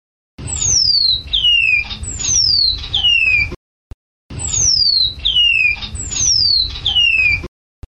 Call 2 - Cock 'classic call'
Loud 2-part call of cock repeated four times, (from cage of 4 unpaired birds)
Cock emitted this pair of 2-part calls in this time scale.   No editing here
call_2_cock_extd.mp3